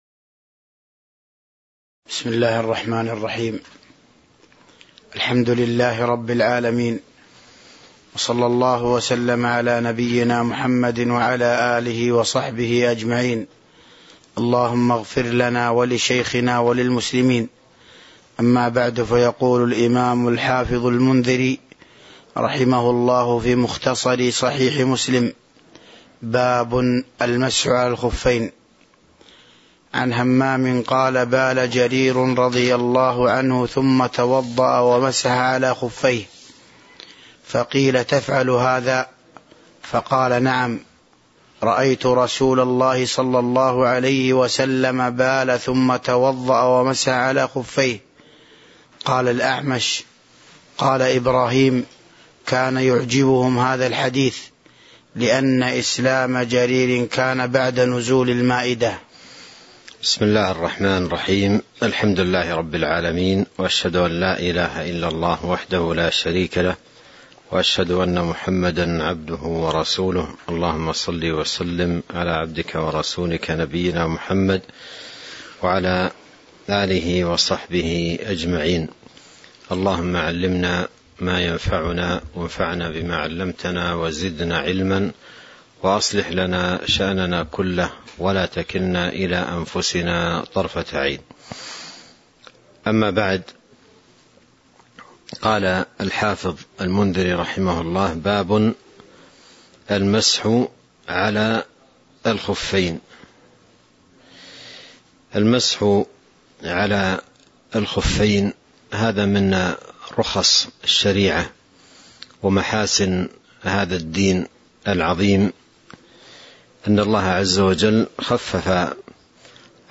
تاريخ النشر ٢ ربيع الثاني ١٤٤٢ هـ المكان: المسجد النبوي الشيخ